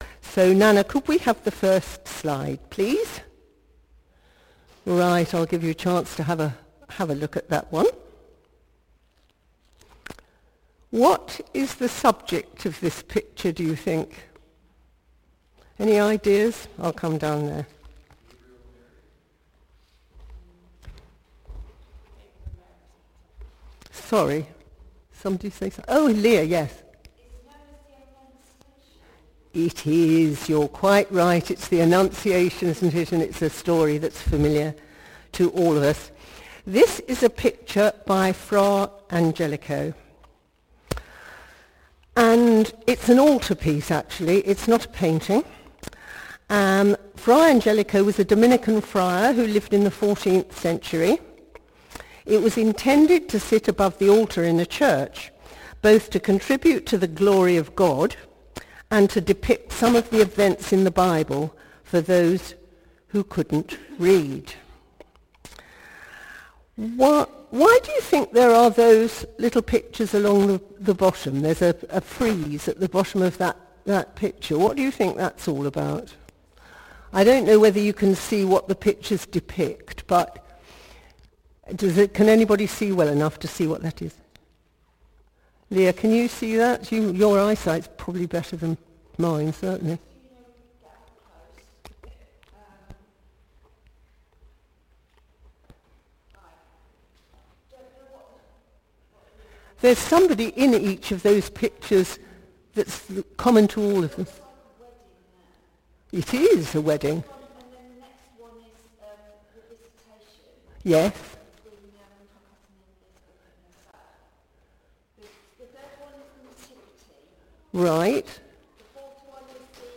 Morning Worship Reflection